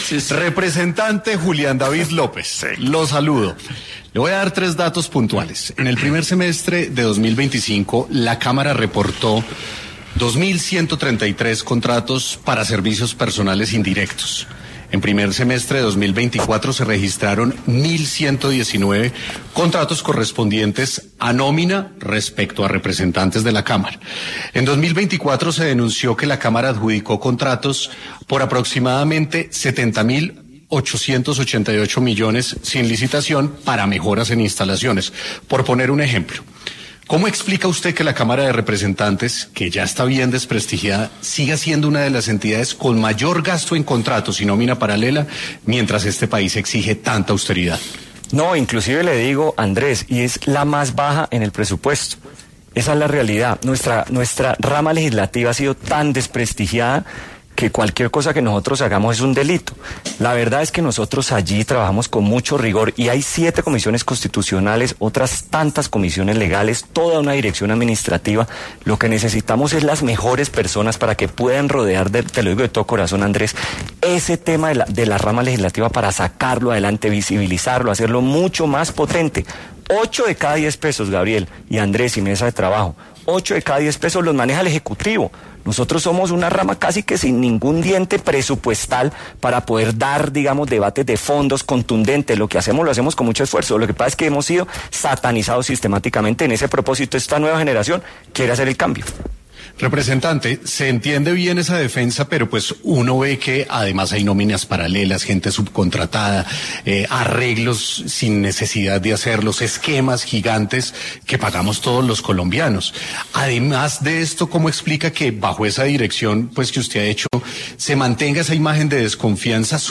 El presidente de la Cámara de Representantes, Julián López, habló acerca de la percepción de la rama legislativa en Sin Anestesia de La Luciérnaga
A lo que el presidente de la Cámara, Julián López, respondió en Sin Anestesia de La Luciérnaga de Caracol Radio.